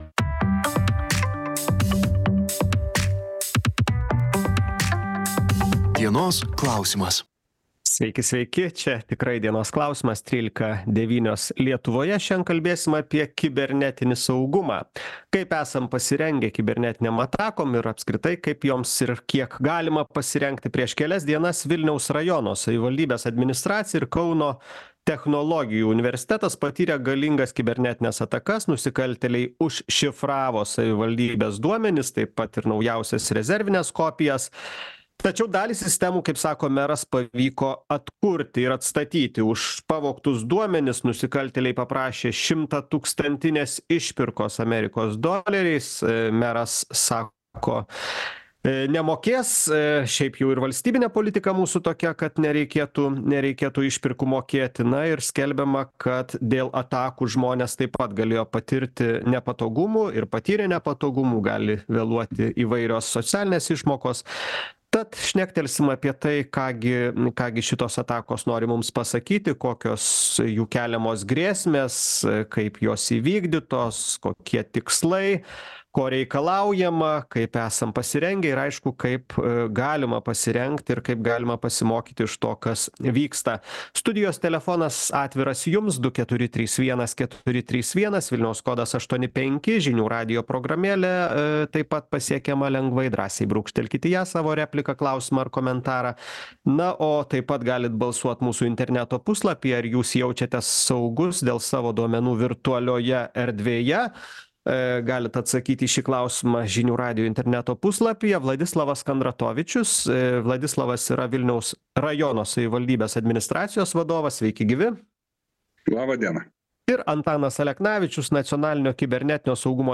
Diskutuoja